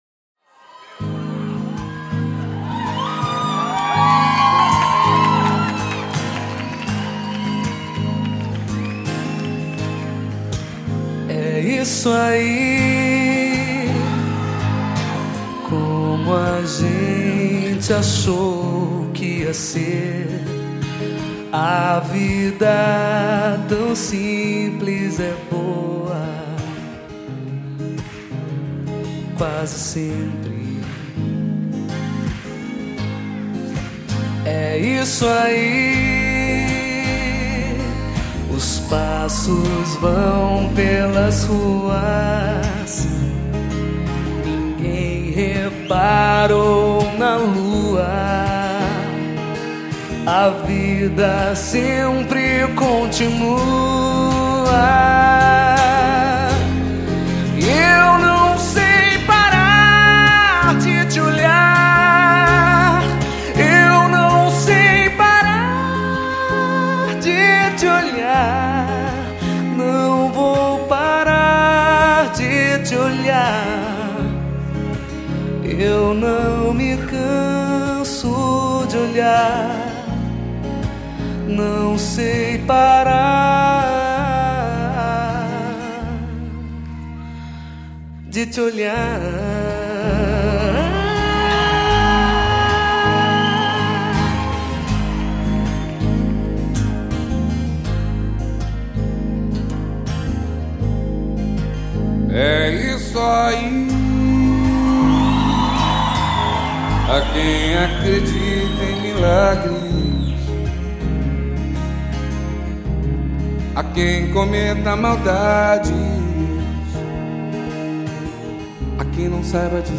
e forse qualcosa di più anche.. la musica è dolcissima
Sono due voci eccezionali.